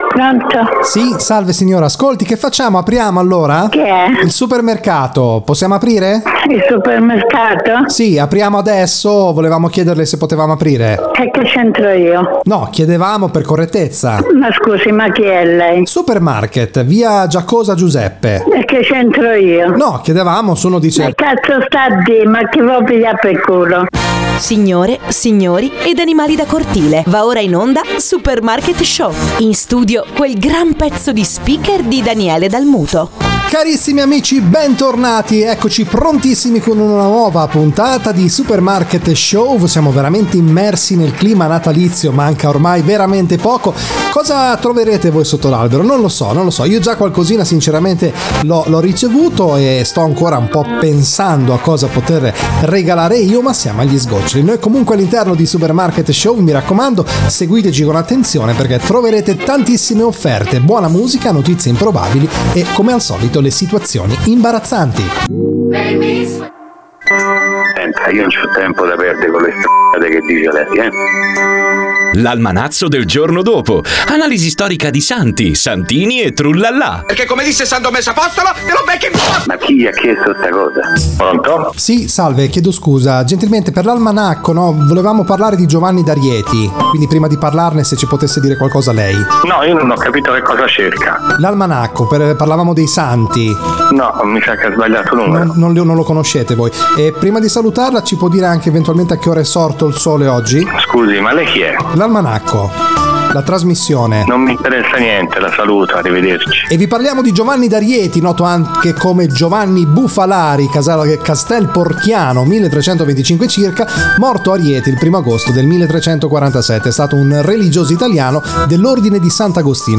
Descrizione: Un programma folle e simpatico con scherzi telefonici e situazioni imbarazzanti in giro per l’Italia.
Musica: Brani in linea con le ultime uscite discografiche, selezionati grazie alla collaborazione con i migliori uffici stampa italiani.
Registrazioni altamente professionali